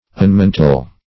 Search Result for " unmantle" : The Collaborative International Dictionary of English v.0.48: Unmantle \Un*man"tle\, v. t. [1st pref. un- + mantle.] To divest of a mantle; to uncover.